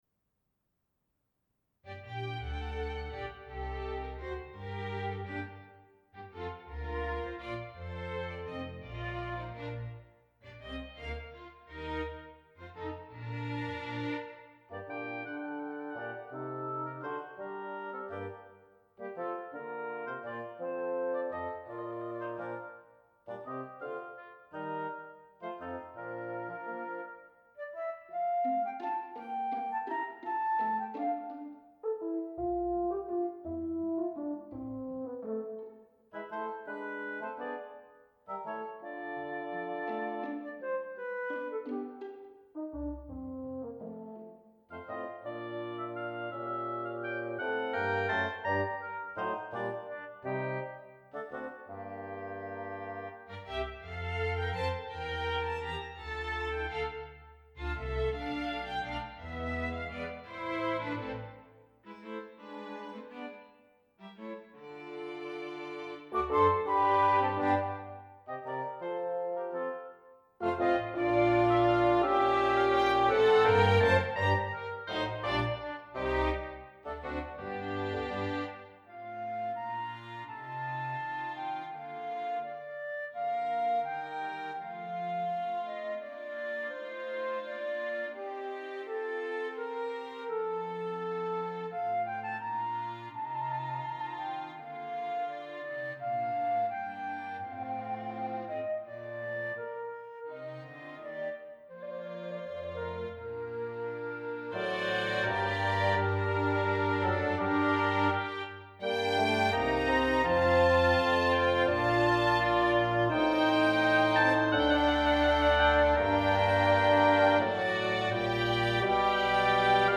Digital Orchestration
Chamber Orchestra
Instrumentation: Flute, Oboe I/II,
Bassoon I/II, Horn in F I/II, Strings